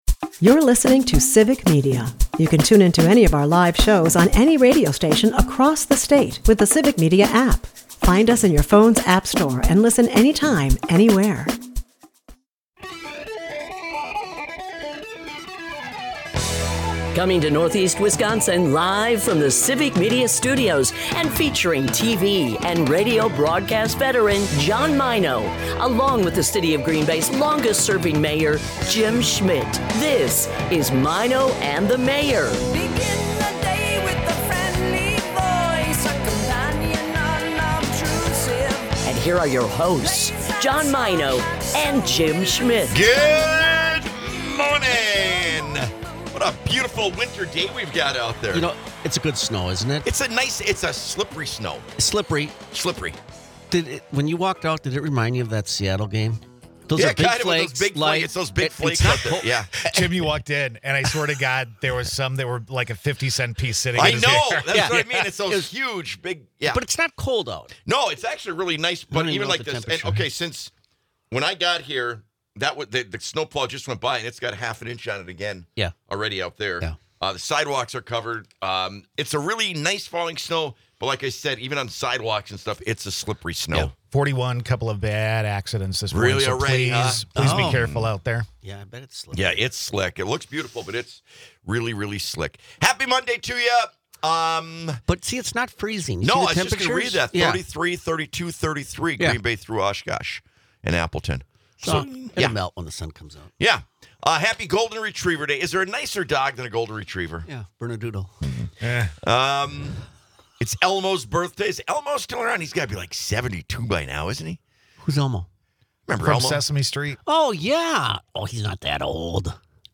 Experience the chemistry and humor between two great friends. Broadcasts live 6 - 9am in Oshkosh, Appleton, Green Bay and surrounding areas.